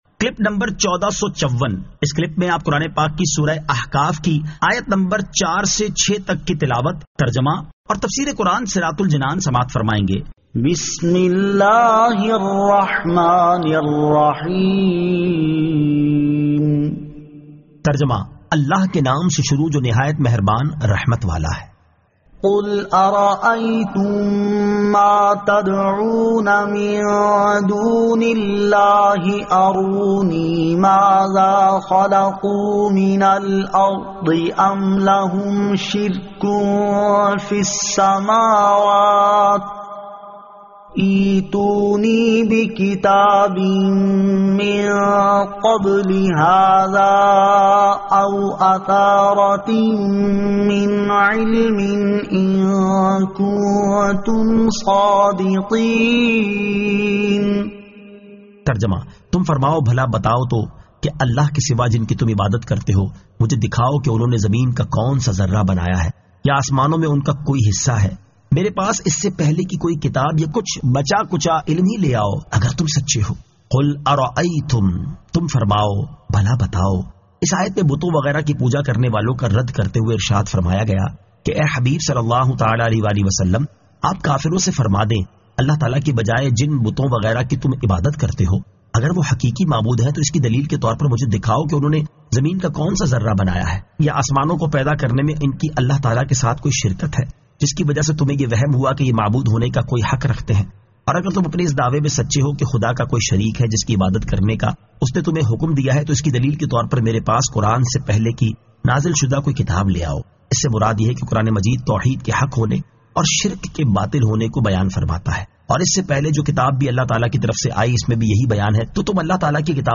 Surah Al-Ahqaf 04 To 06 Tilawat , Tarjama , Tafseer
2024 MP3 MP4 MP4 Share سُوَّرۃُ الاٗحقَاف آیت 04 تا 06 تلاوت ، ترجمہ ، تفسیر ۔